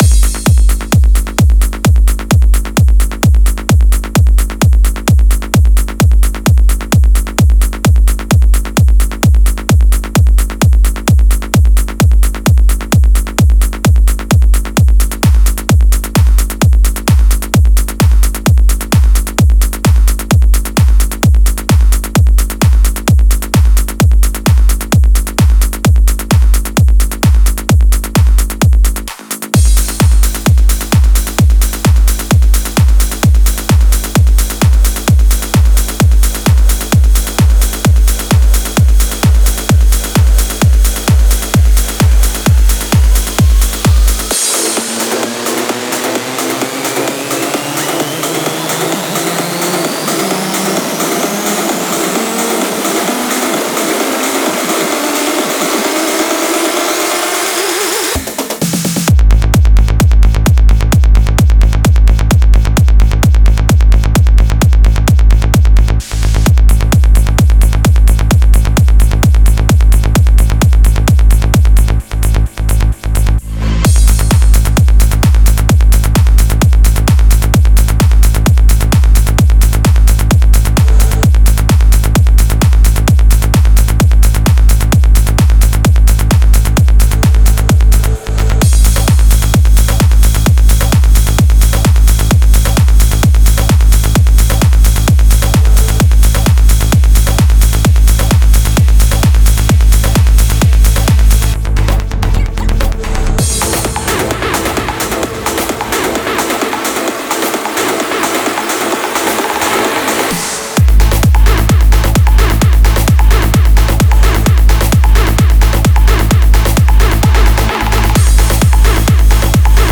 Стиль: Trance / Tech Trance